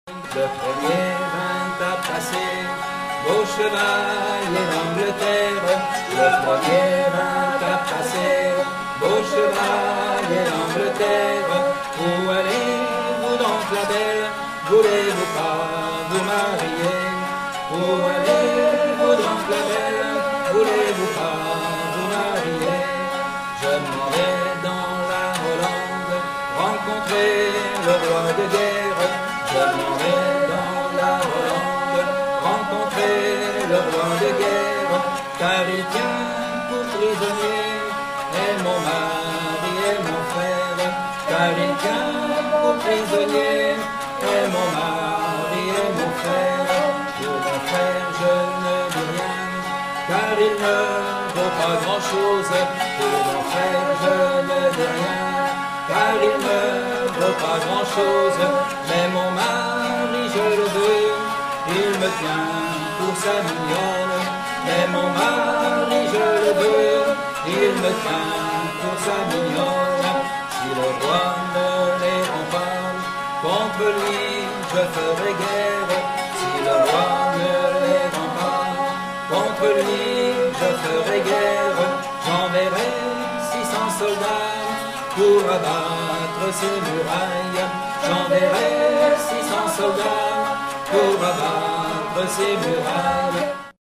Sur_le_pont_de_Lyon_mazurka_extrait.mp3